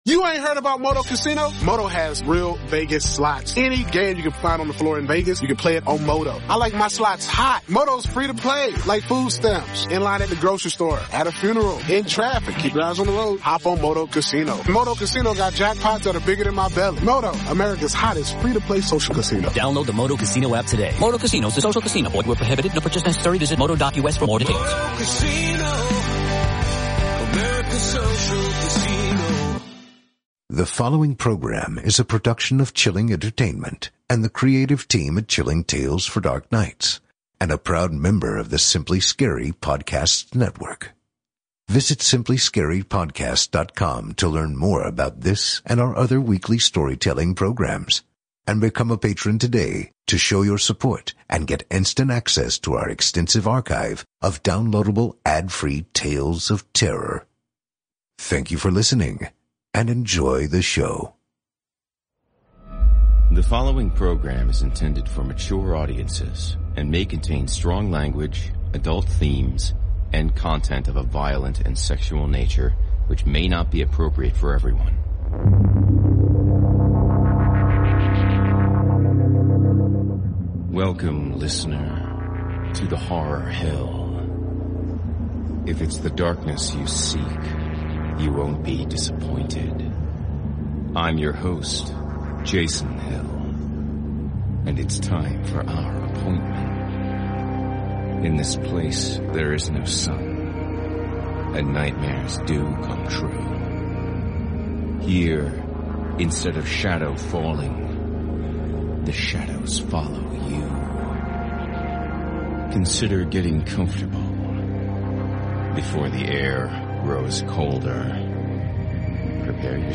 performed by host and narrator